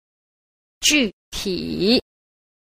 5. 具體 – jùtǐ – cụ thể
Cách đọc: